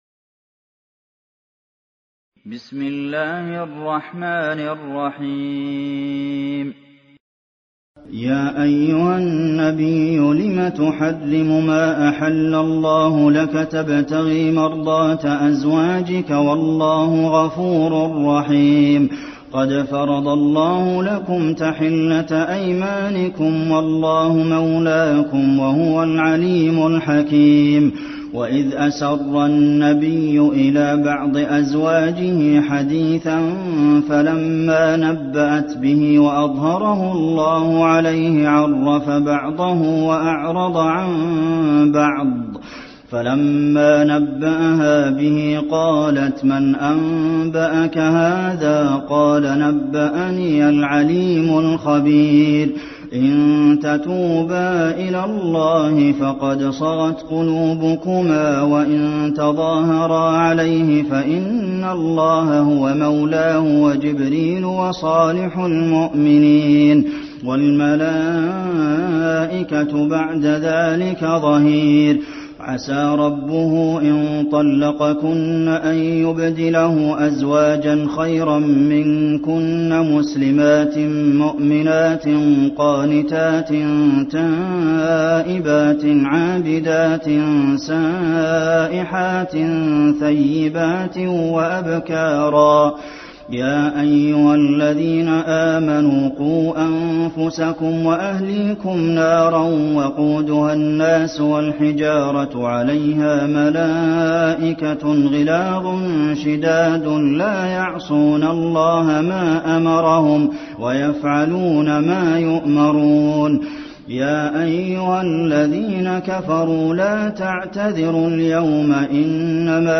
المكان: المسجد النبوي التحريم